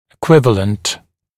[ɪ’kwɪvələnt] [и’куивэлэнт] эквивалент